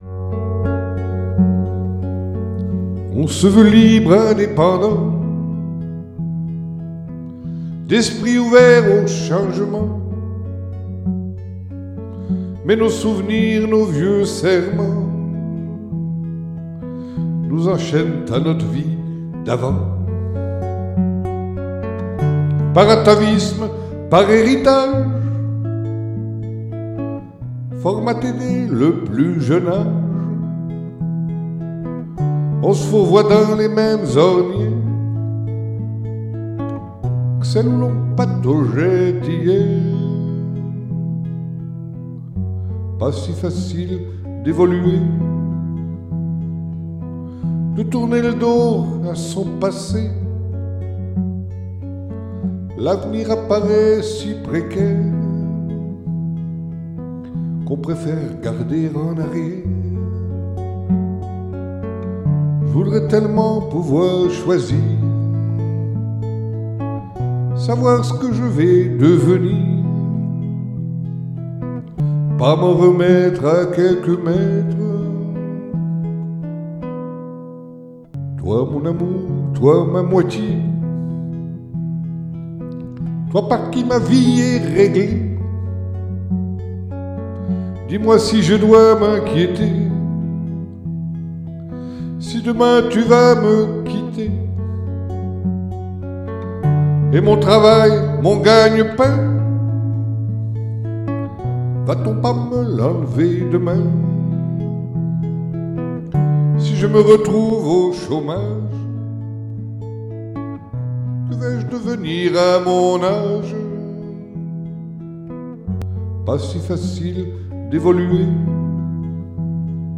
[Capo 2°]